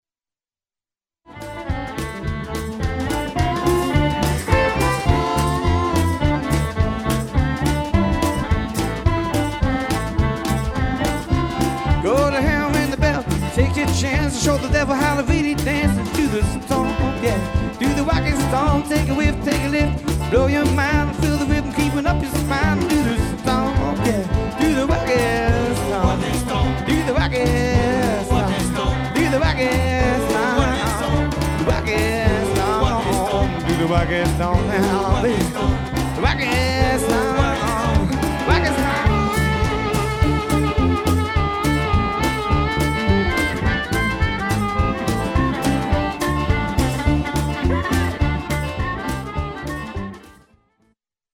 Grande soirée consacrée au blues
mandoline acoustique et électrique, guitare électrique
harmonicas
basse électrique, contrebasse
accordéon
batterie, percussions